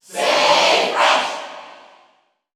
Category: Inkling (SSBU) Category: Crowd cheers (SSBU) You cannot overwrite this file.
Inkling_Cheer_English_SSBU.ogg